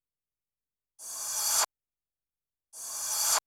REVERSE HA-R.wav